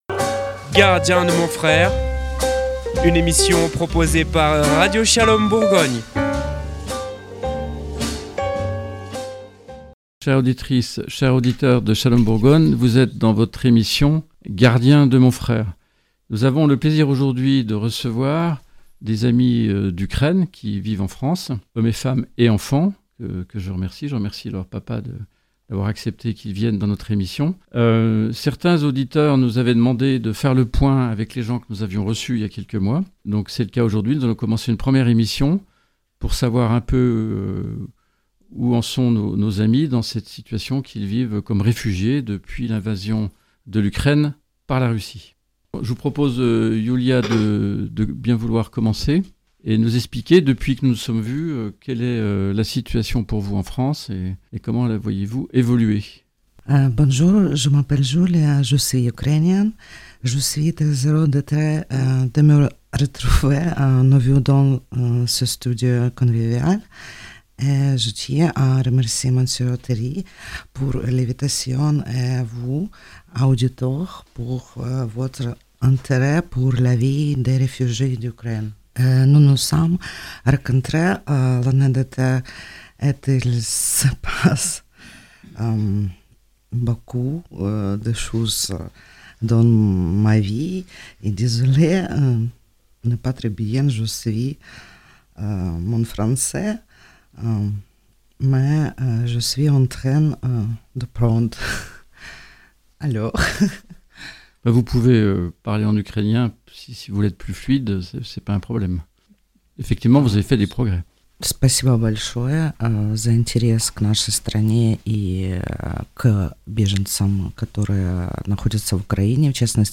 04 mars 2024 Écouter le podcast Télécharger le podcast "Gardien de mon frère" Radio Shalom Bourgogne continue en 2024 une série d'émissions avec le Groupe de paroles de Réfugiés Ukrainiennes et Ukrainiens qui sont en France depuis l'invasion russe de 2022. En introduction, vous trouverez des rappels sur l'évolution de la situation des réfugiés en France dont le nombre est faible comparé aux autres pays européens.